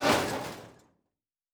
pgs/Assets/Audio/Sci-Fi Sounds/MISC/Metal Foley 3.wav at master
Metal Foley 3.wav